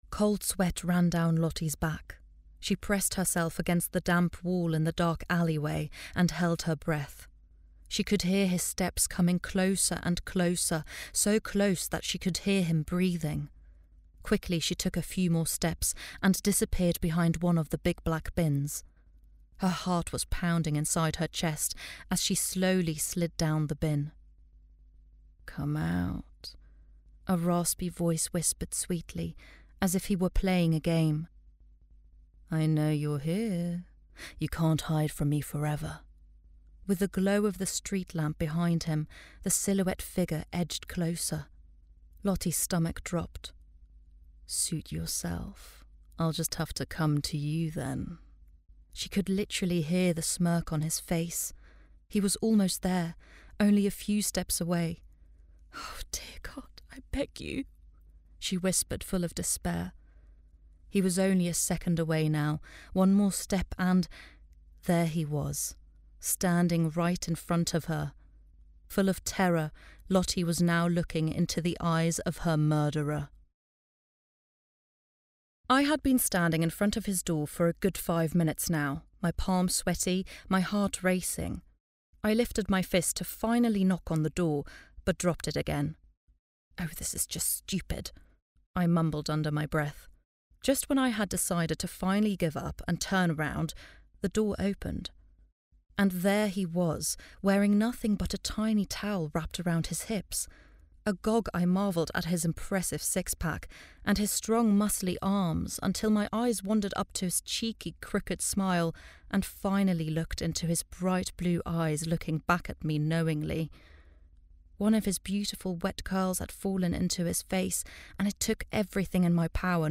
German, Versatile, Assured, Natural, Clear, Warm
Audiobook